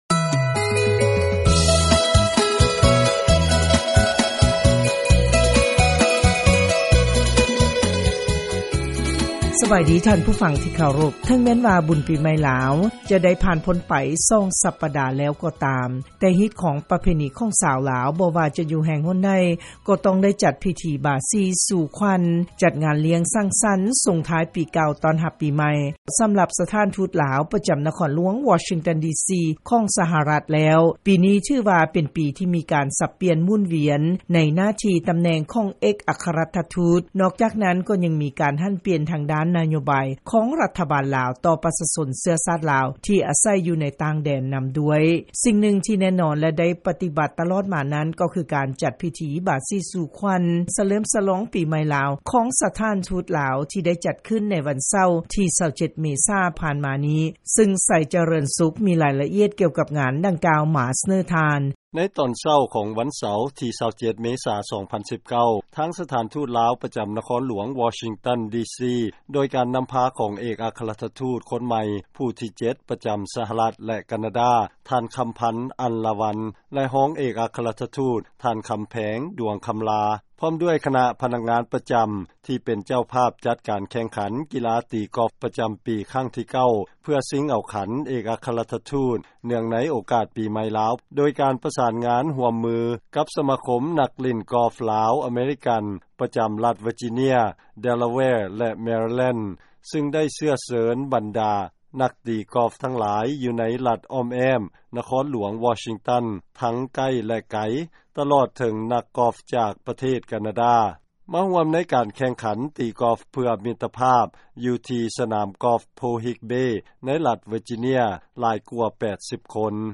ງານສະເຫຼີມສະຫຼອງ ປີໃໝ່ລາວ ພສ 2562 ຢູ່ທີ່ ສະຖານທູດລາວ ໃນນະຄອນຫຼວງ ວໍຊິງຕັນ ດີຊີ